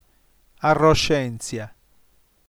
arroscèntzia nf ar.ro.scén.tzi(.)a - [ar:o'ʃentsja/-tsia] ◊